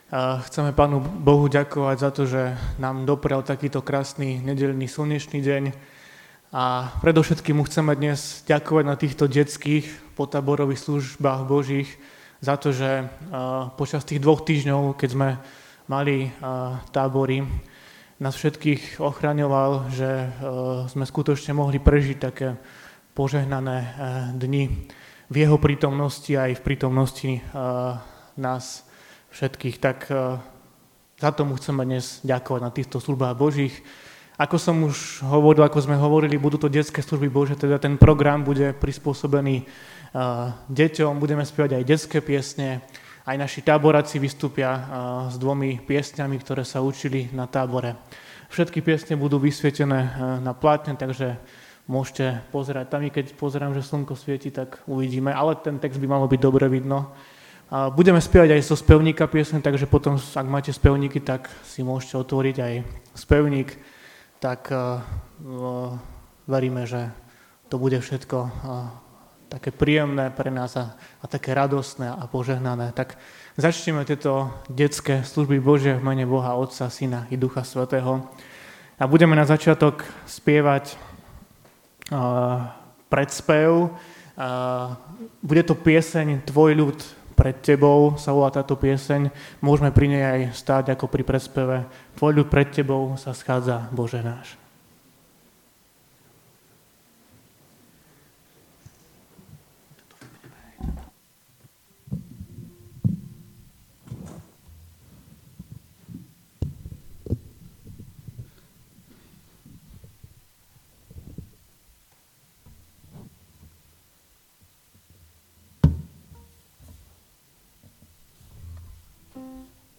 7. nedeľa po Svätej Trojici 2020 – Detské potáborové SB